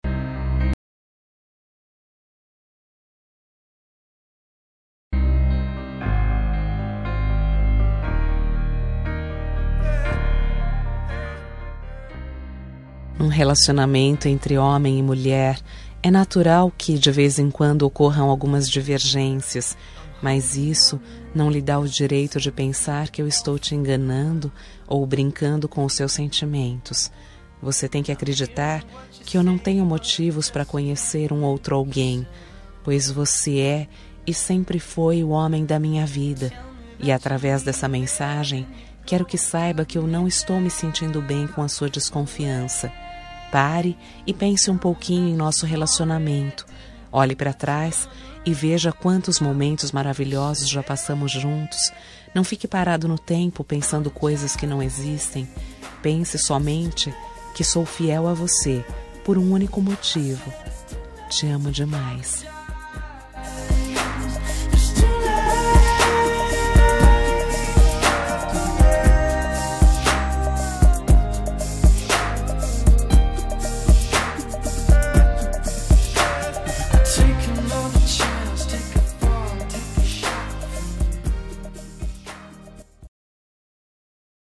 Toque para Não Terminar – Voz Feminina – Cód: 475 – Confie em Mim
475-confie-em-mim-fem.m4a